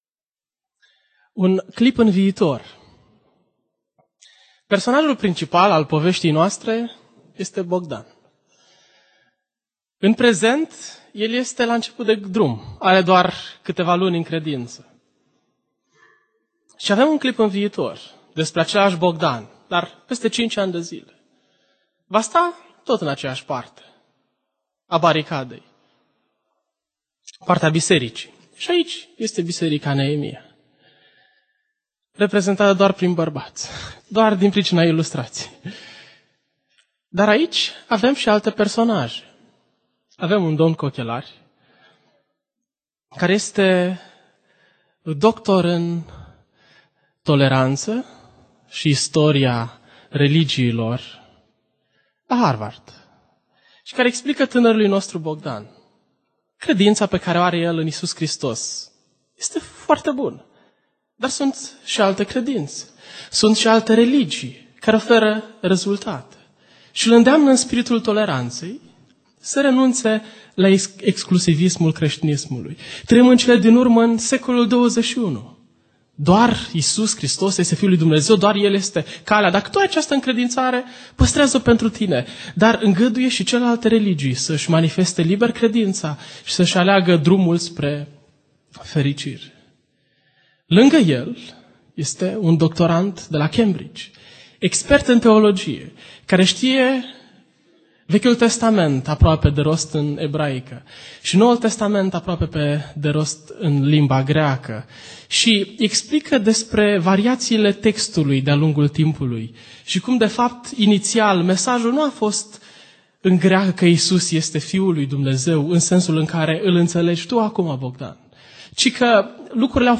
Recapitulare Evanghelia dupa Ioan aplicatie, predici evanghelia dupa ioan, evanghelia dupa ioan